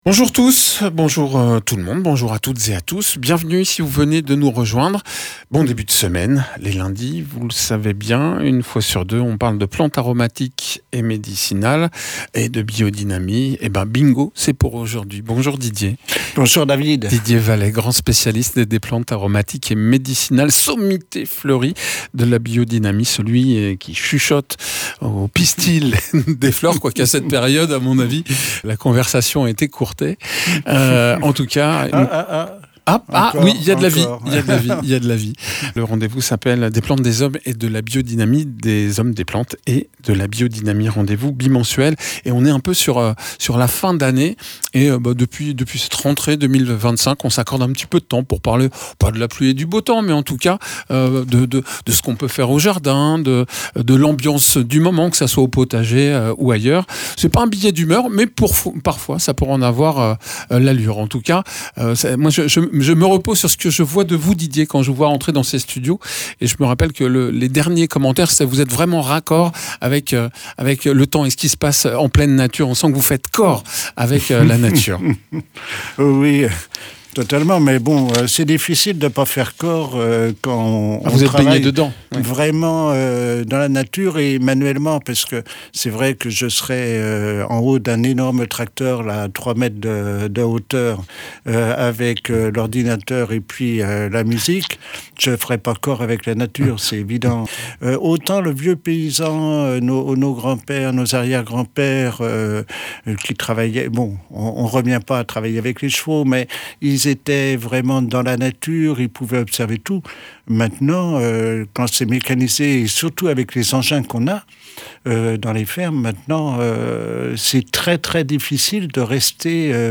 paysan spécialisé dans les plantes aromatiques et médicinales et la biodynamie